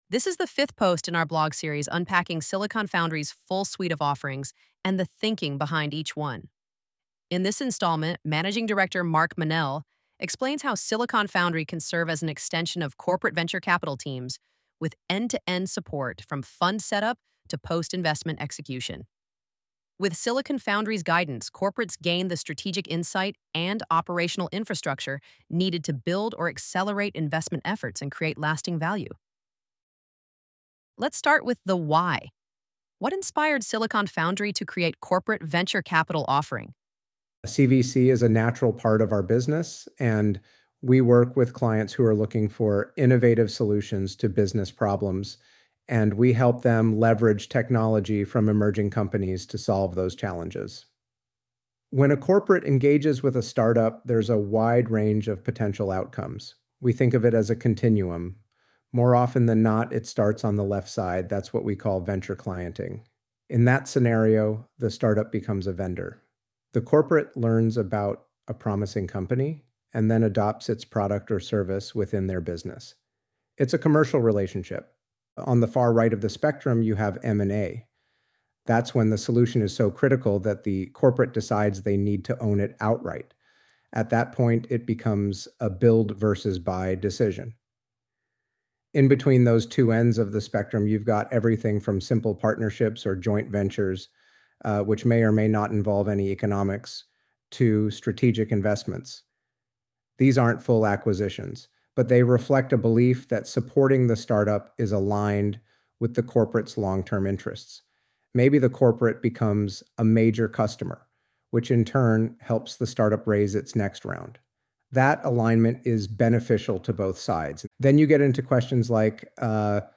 ElevenLabs_Our_Offerings_CVC.mp3